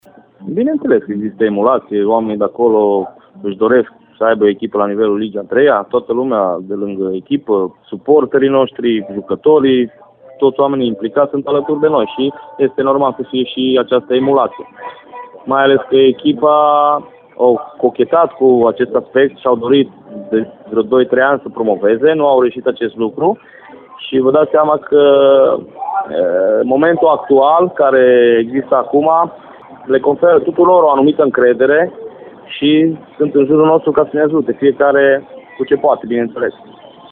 Interviul complet